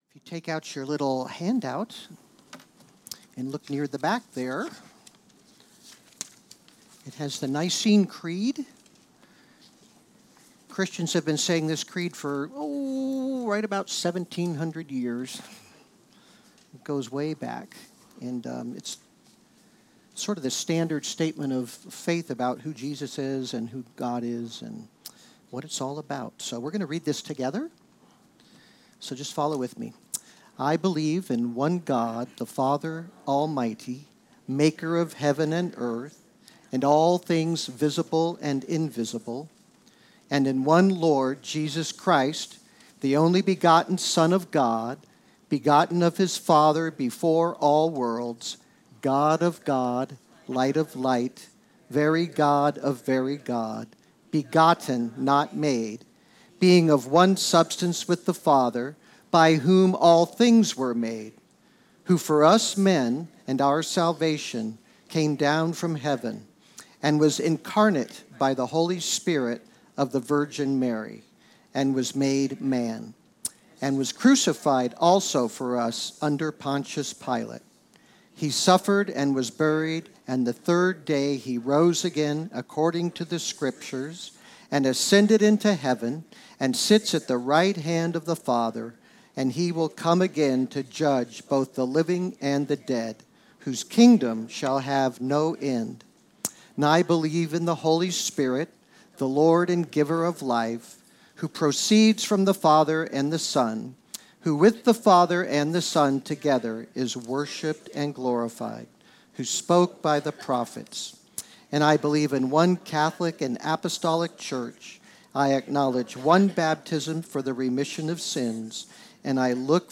Tonight, on Christmas Eve, we start off with the Nicene Creed